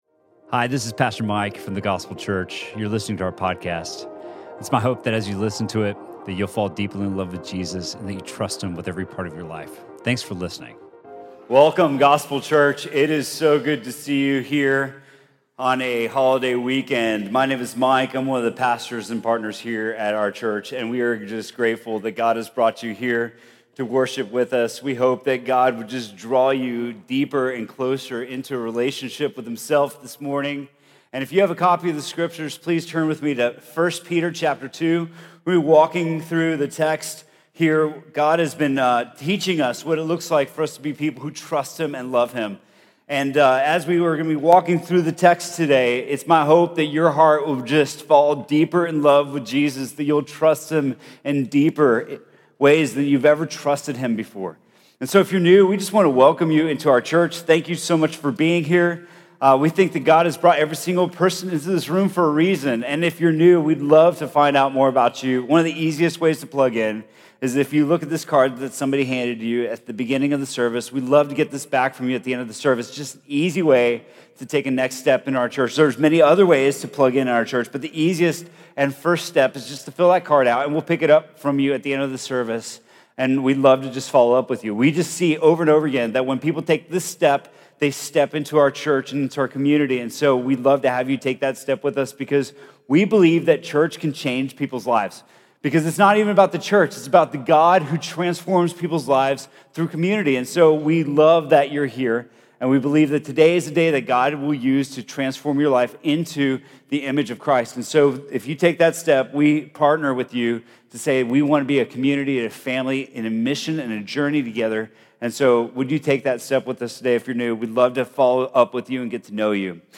Sermon from The Gospel Church on September 2nd, 2018.